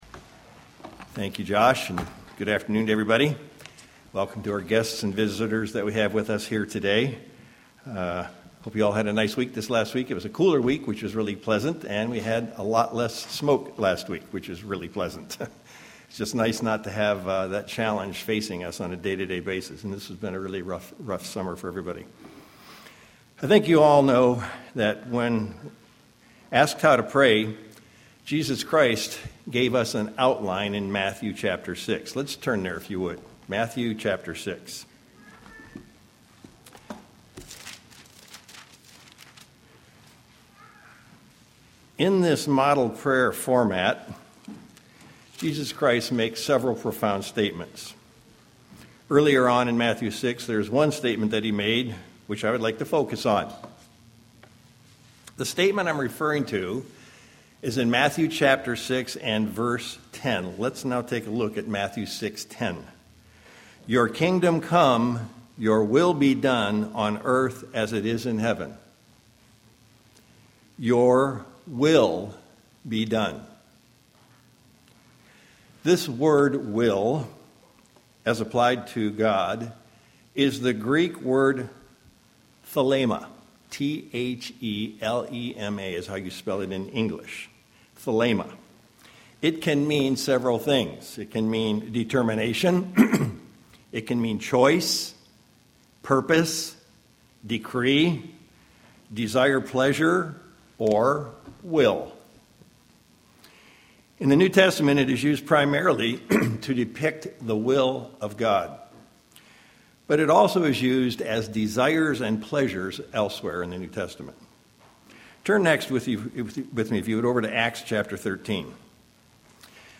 There is a way to know it, and it comes in many forms. This sermon will address several of these areas in our search for God's Will.
Given in Sacramento, CA